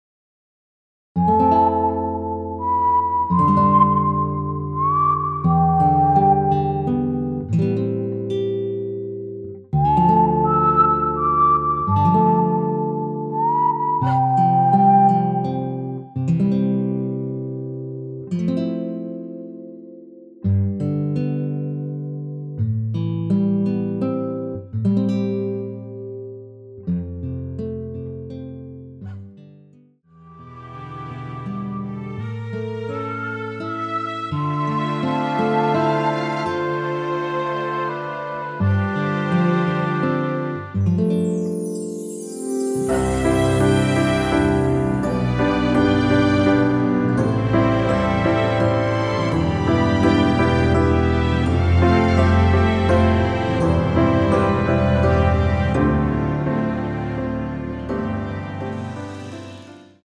무반주 구간 들어가는 부분과 박자 맞출수 있게 쉐이커로 박자 넣어 놓았습니다.(일반 MR 미리듣기 참조)
◈ 곡명 옆 (-1)은 반음 내림, (+1)은 반음 올림 입니다.
앞부분30초, 뒷부분30초씩 편집해서 올려 드리고 있습니다.
중간에 음이 끈어지고 다시 나오는 이유는